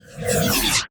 ui_menu_scan_01.wav